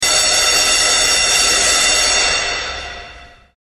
Klingeltöne
😉 Schelle Neue Uni Räumung der Neuen Uni 2009
Schelle_Neue_Uni.mp3